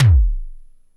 SIMMONS SDS7 1.wav